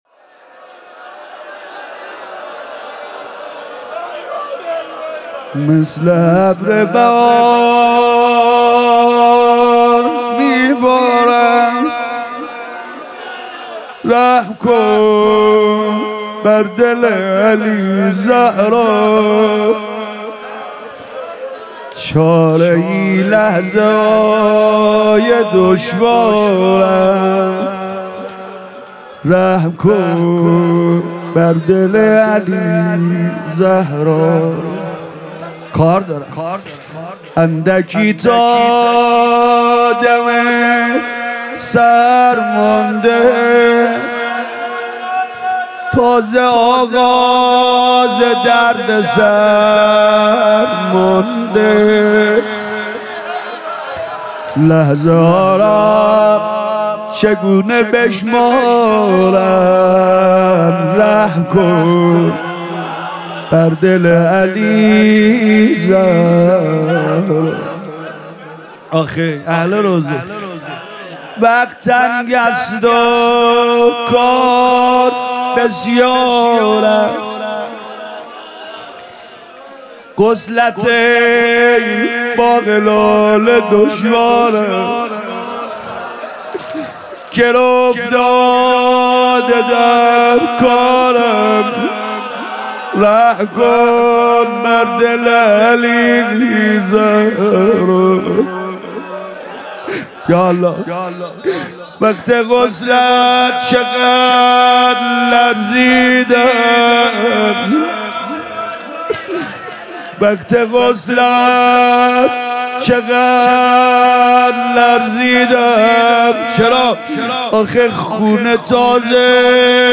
فاطمیه 96 - روضه - مثل ابر بهار می بارم رحم کن بر دل علی زهرا